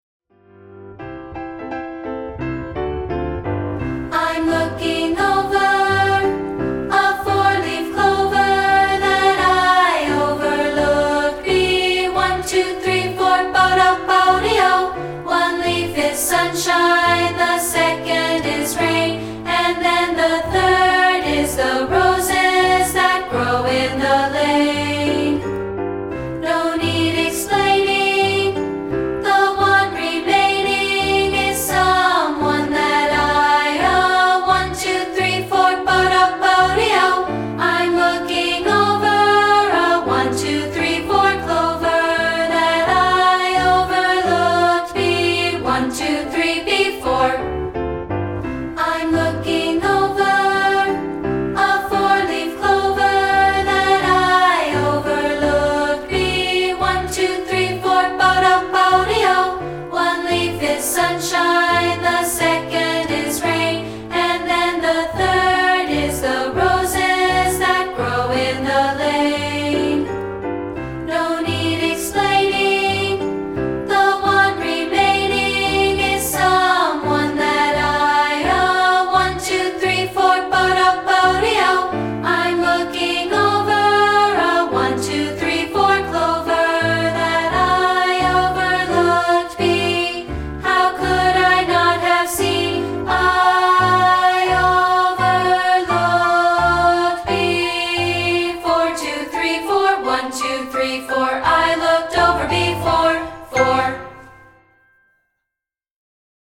No. 4 and made it available as a free rehearsal track.